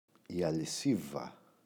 αλισίβα, η [aliꞋsiva] – ΔΠΗ